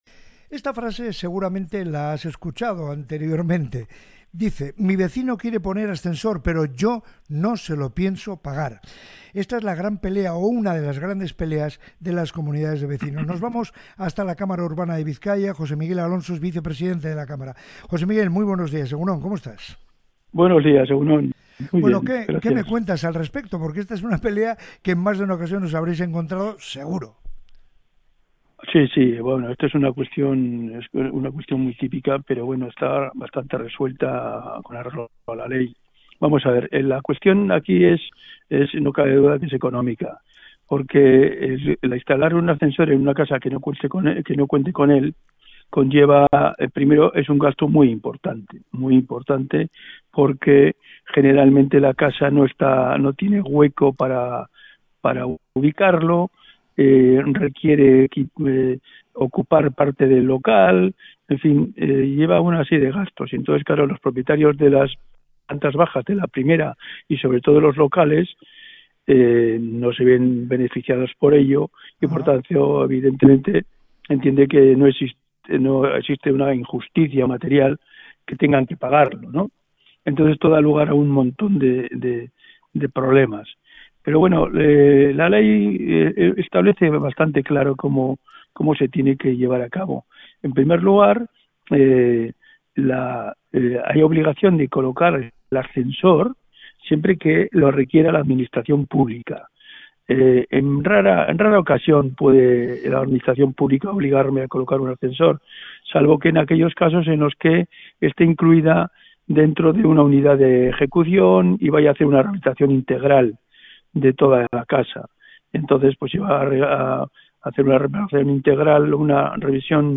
Entrevista a la cámara de la propiedad urbana